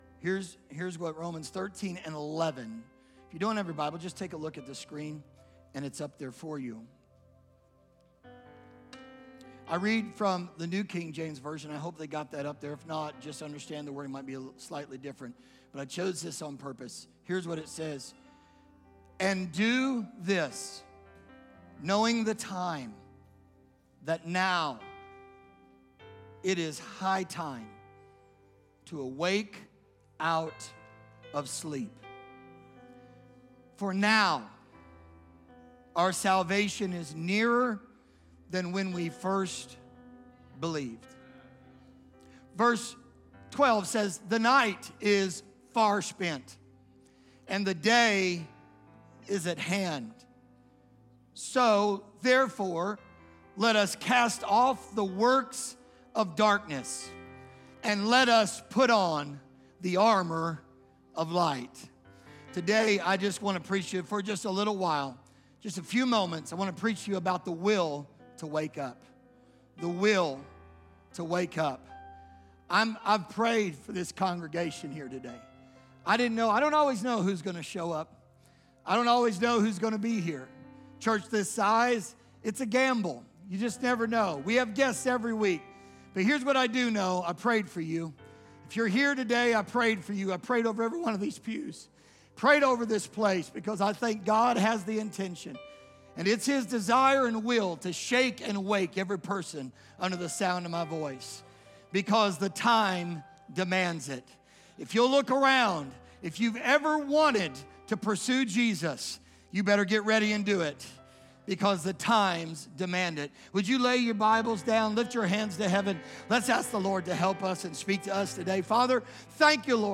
Sunday Morning Service, Apostolic Pentecostal Church.